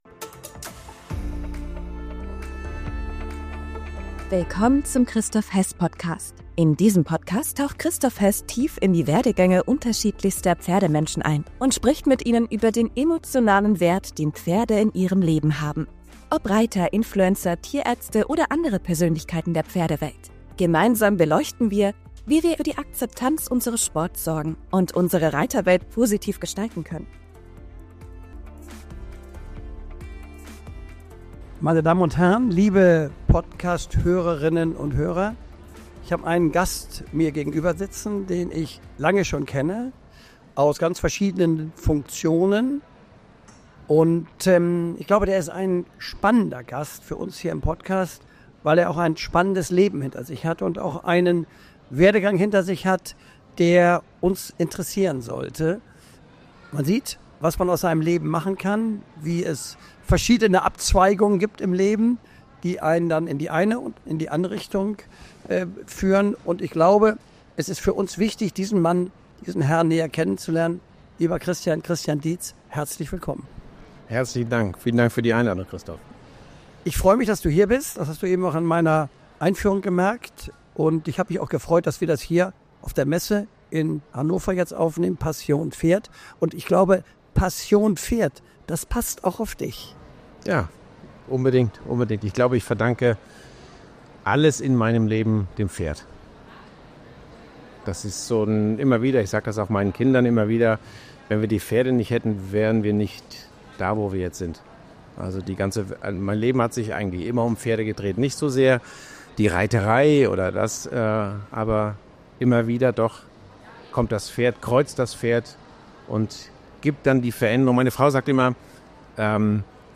Diese Podcastfolge wurde live auf der Messe Passion Pferd in Hannover aufgezeichnet.